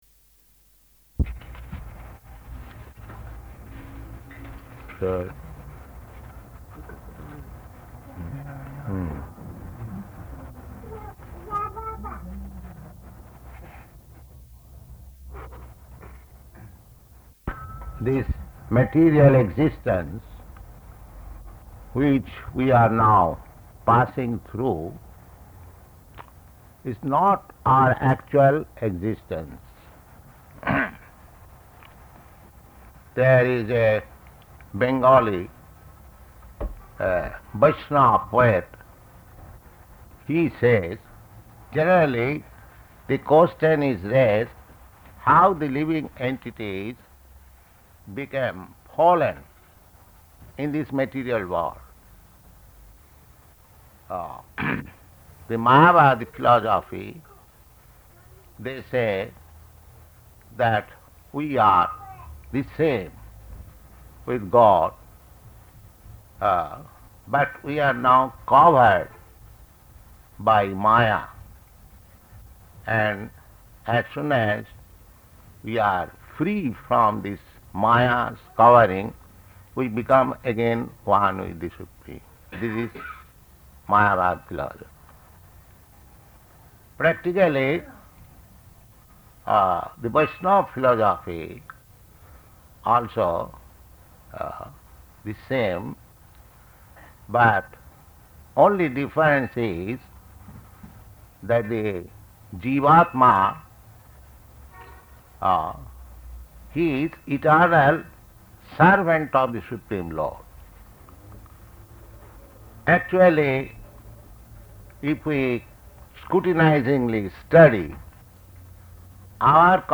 Lecture
Lecture --:-- --:-- Type: Lectures and Addresses Dated: May 1st 1972 Location: Tokyo Audio file: 720501LE.TOK.mp3 Prabhupāda: So...